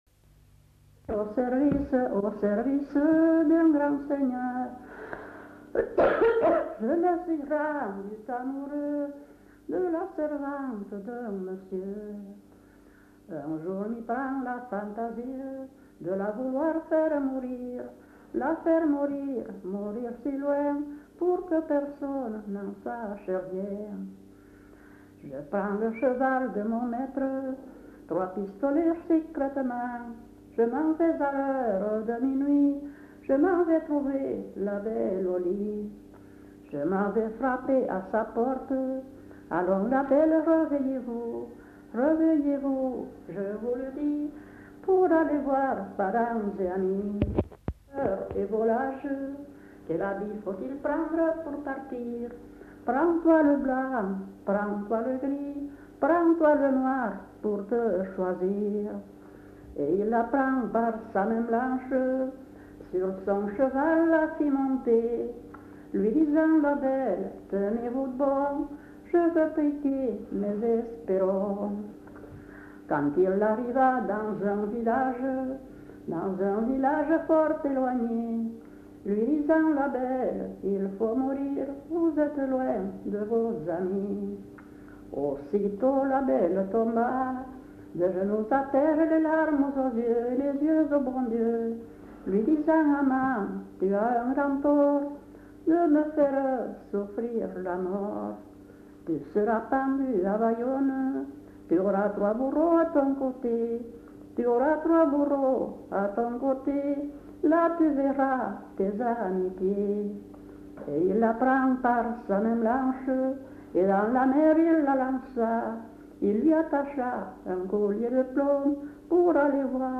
[Brocas. Groupe folklorique] (interprète)
Lieu : [sans lieu] ; Landes
Genre : chant
Effectif : 1
Type de voix : voix de femme
Production du son : chanté